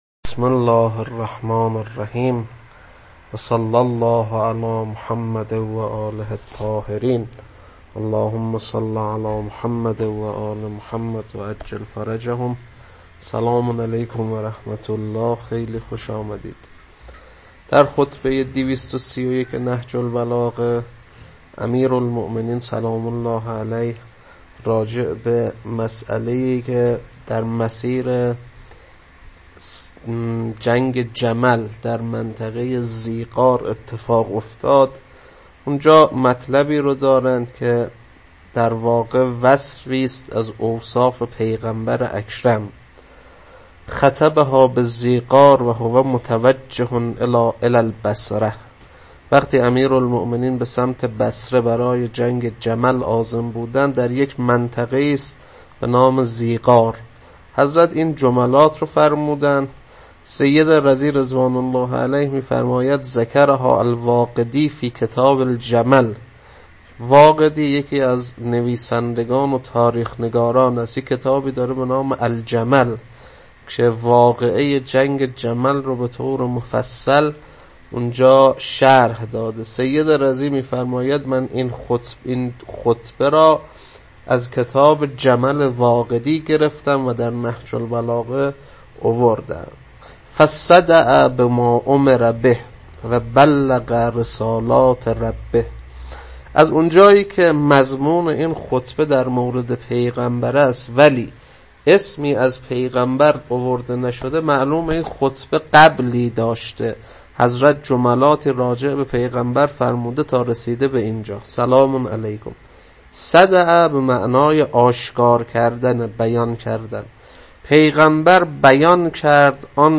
خطبه 231.mp3